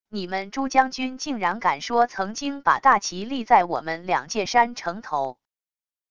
你们珠江军竟然敢说曾经把大旗立在我们两界山城头wav音频生成系统WAV Audio Player